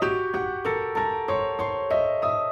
Index of /musicradar/gangster-sting-samples/95bpm Loops
GS_Piano_95-G2.wav